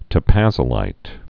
(tə-păzə-līt)